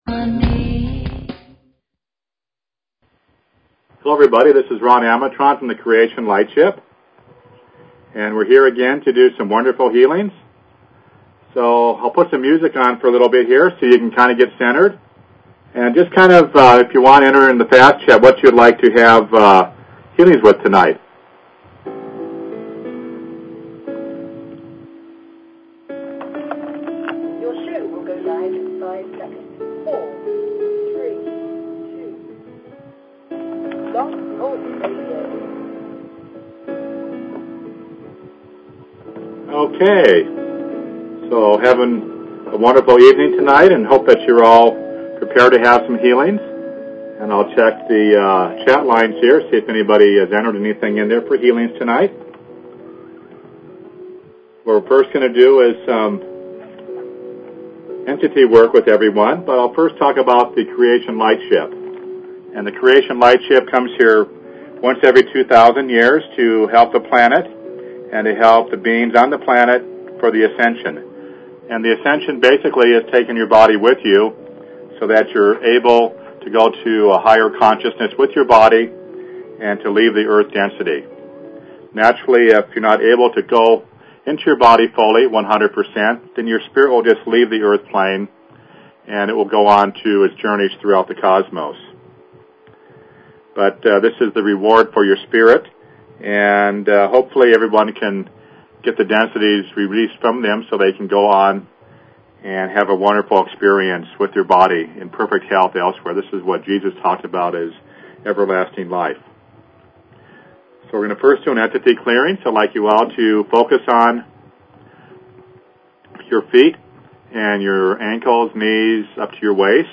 Talk Show Episode, Audio Podcast, Creation_Lightship_Healings and Courtesy of BBS Radio on , show guests , about , categorized as